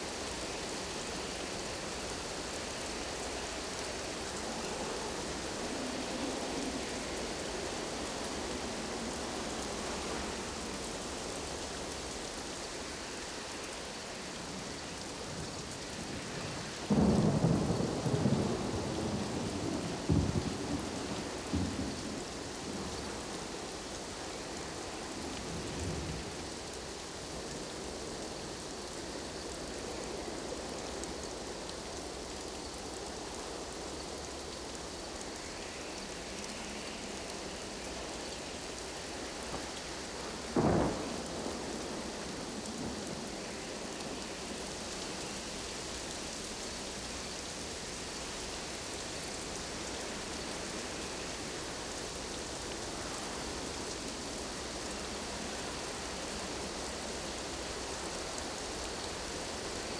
Forest Night Storm.ogg